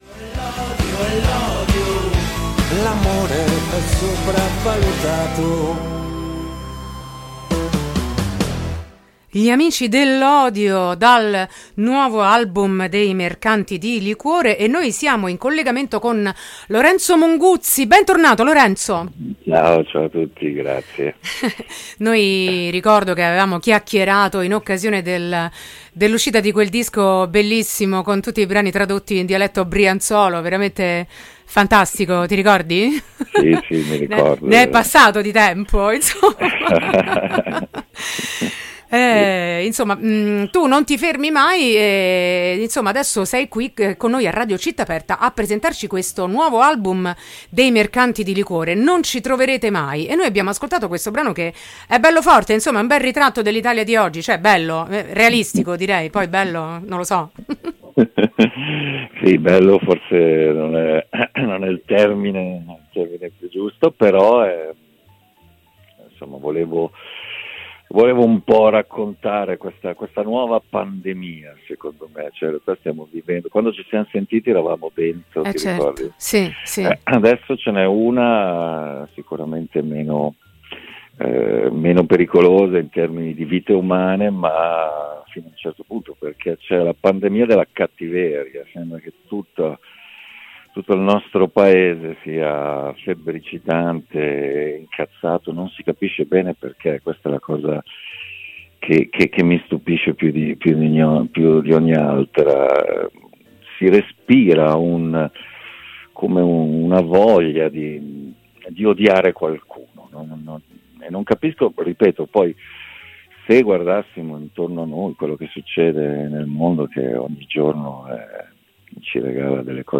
intervista-mercantidiliquore-16-5-25.mp3